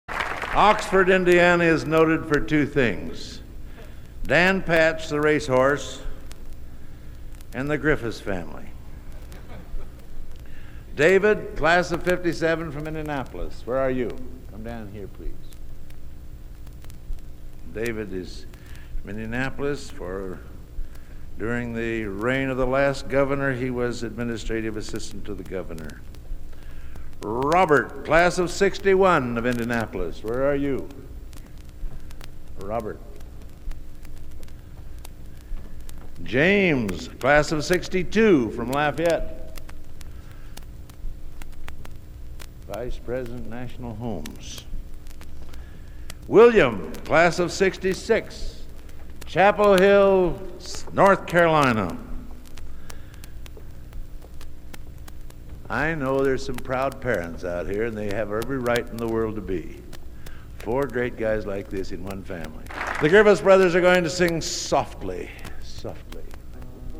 Genre: | Type: Director intros, emceeing